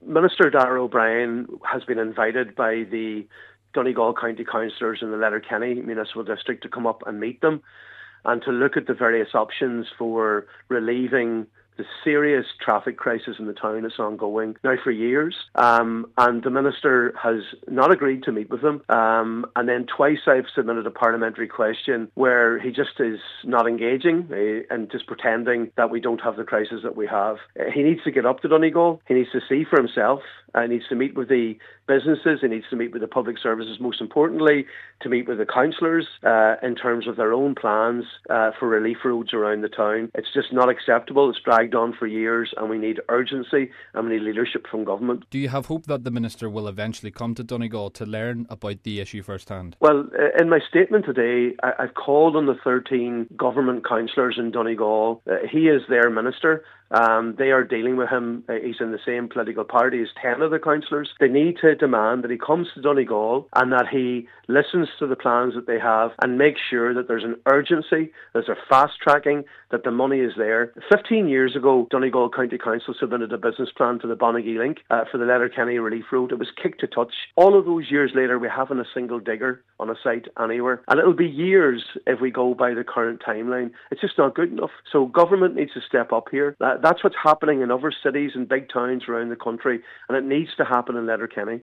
Deputy Pádraig Mac Lochlainn is once more calling on the Minister to make a visit: